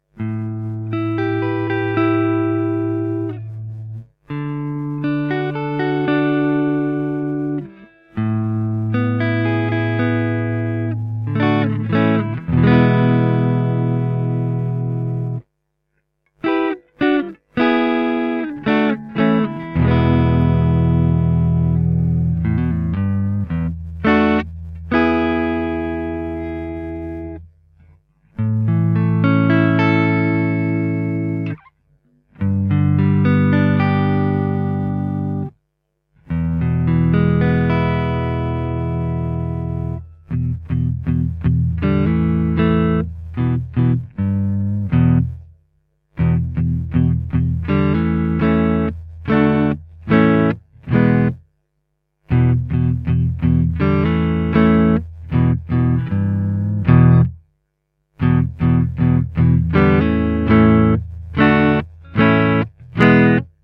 The Yellow Dog offers more bite and punch in the top end, together with plenty of warmth in the lower registers.